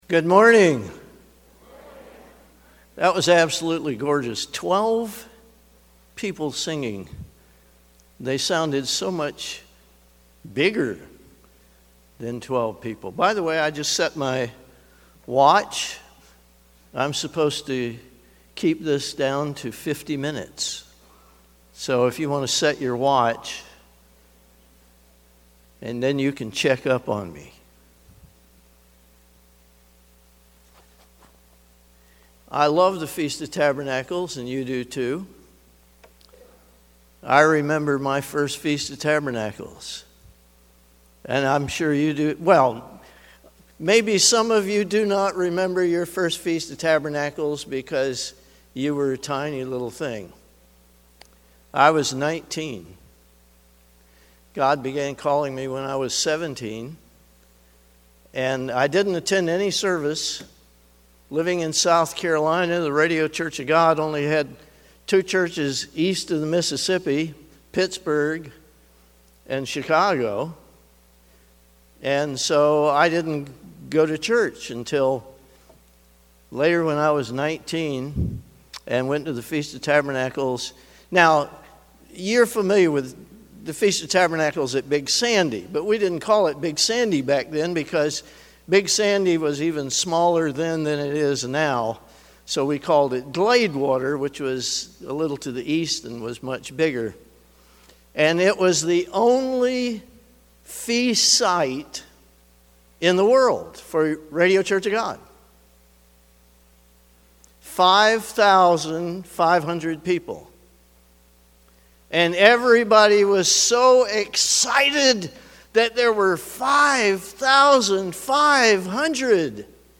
This sermon was given at the Ocean City, Maryland 2022 Feast site.